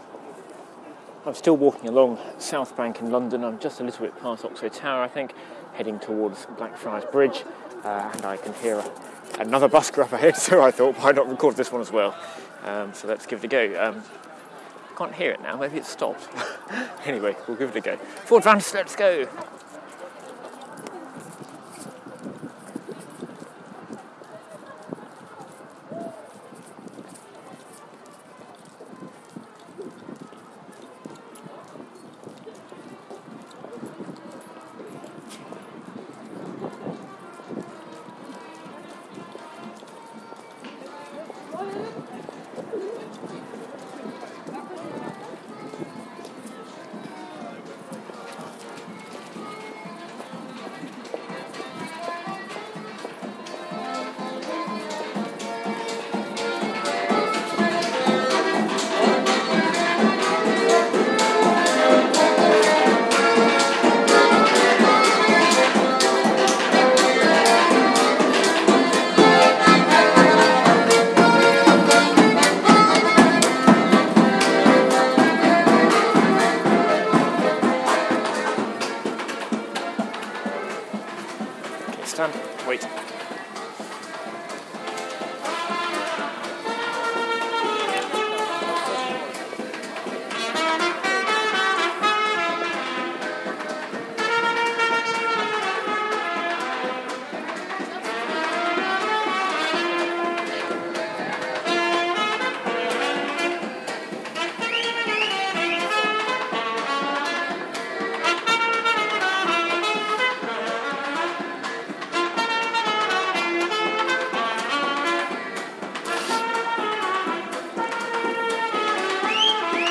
Busking beneath Blackfriars Bridge